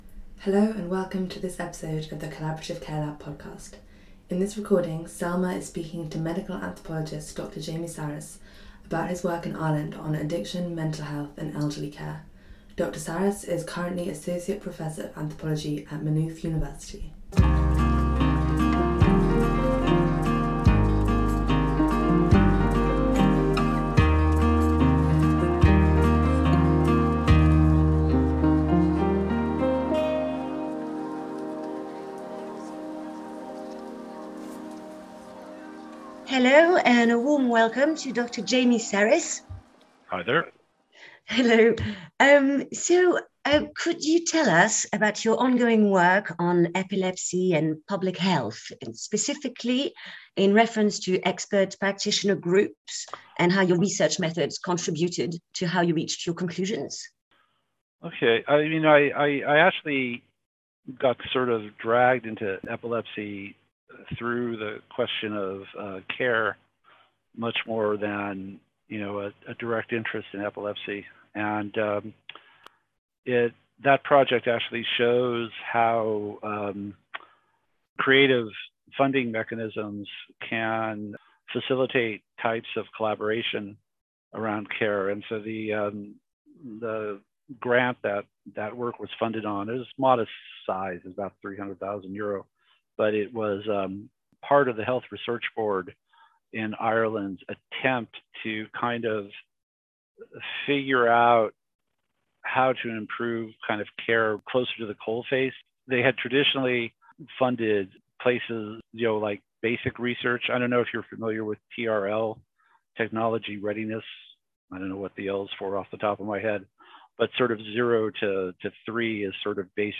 Collaborative Care Lab interview